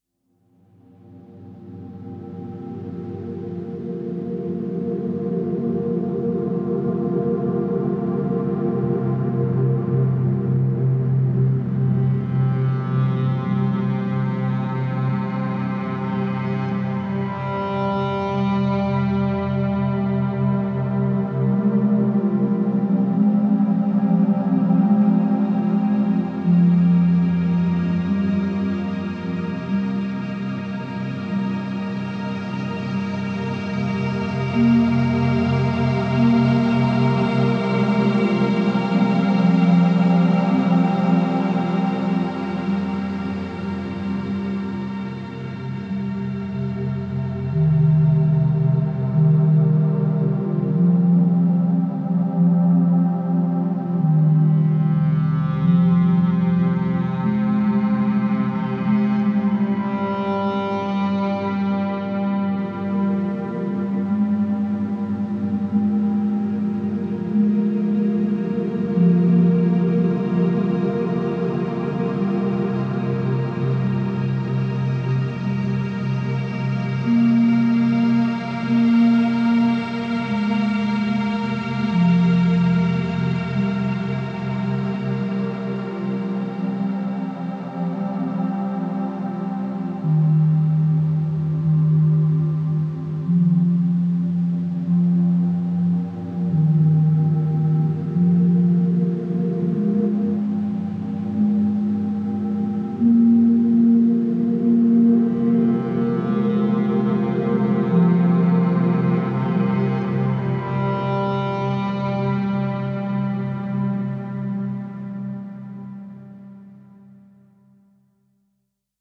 Melancholic cello and strings float gently in the night.